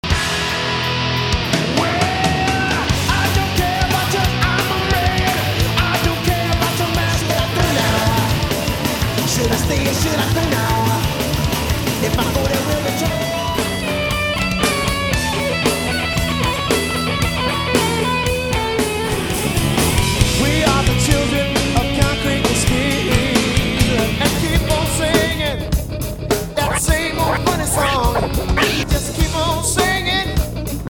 So, basically it’s an EP with a concert album bolted on.